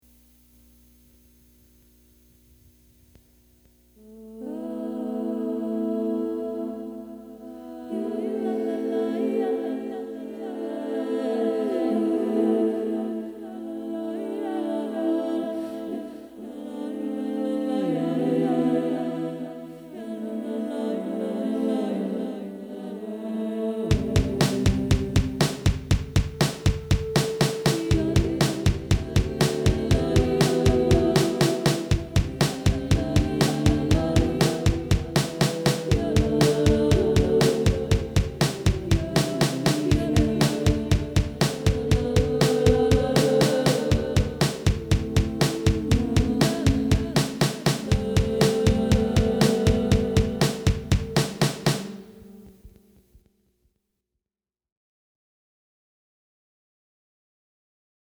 Canciones para voz, pianos y electrodomésticos.
voice rendition